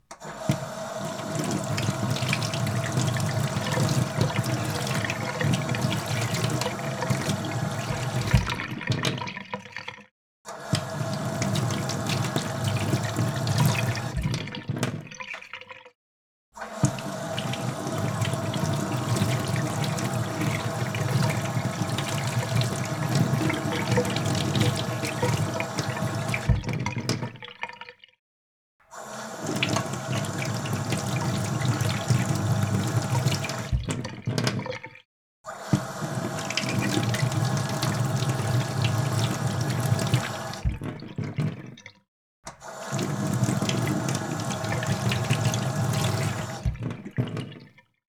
Cooking Noise Sound
household